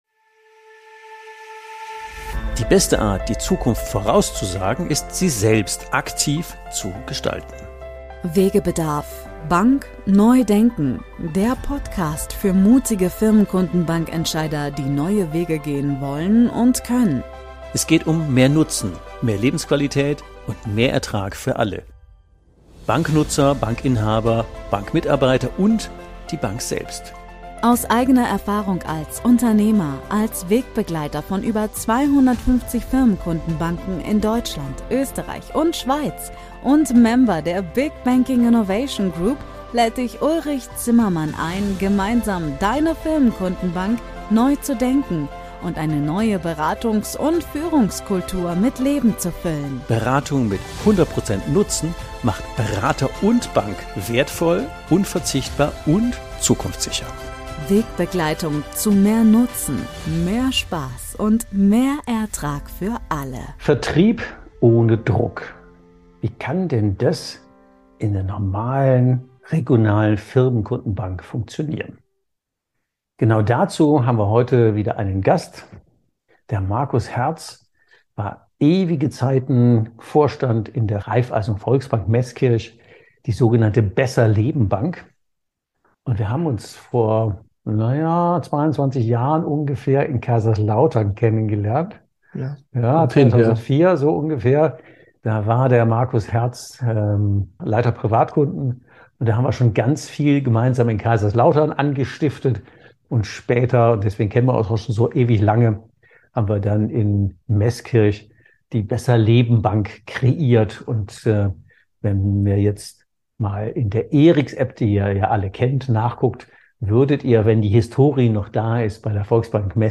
#055 Vertrieb ohne Druck. Warum man mit Nutzen viel erfolgreicher wird. Die BesserLeben-Bank - im Gespräch